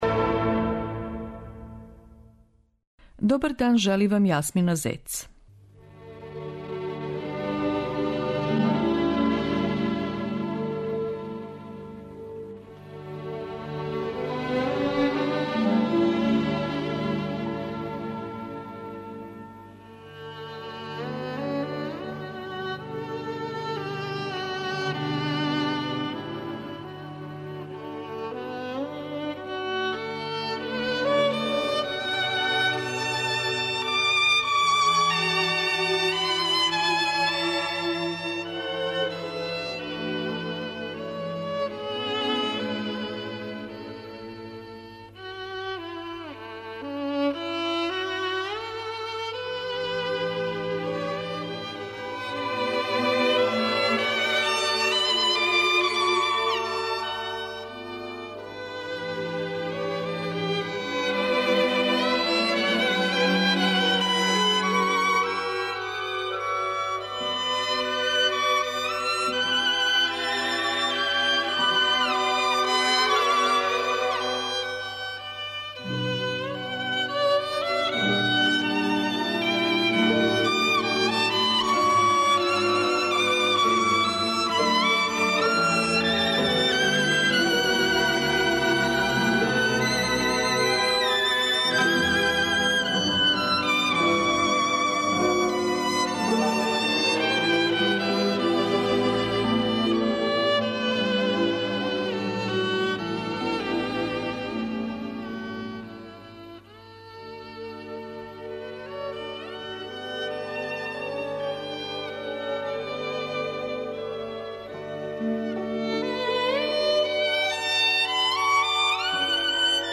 Слушаћете виолинисту Леонида Когана